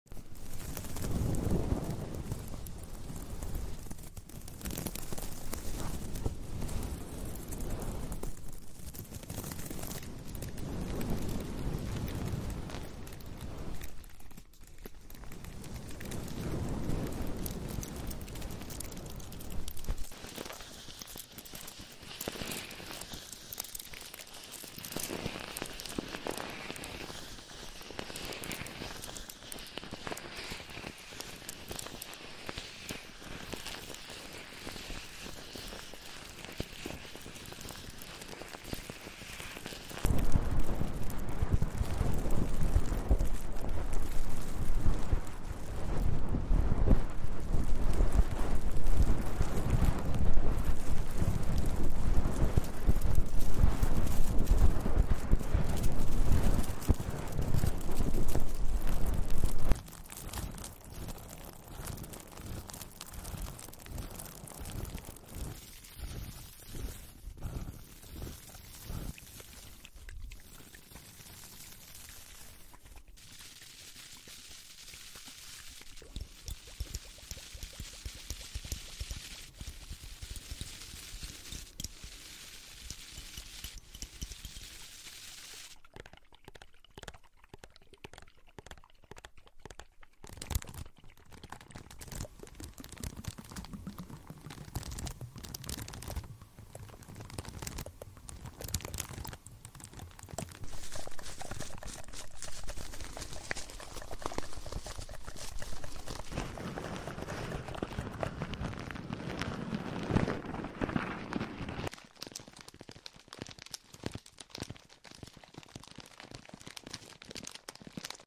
Layered ASMR Sounds 💖 Follow sound effects free download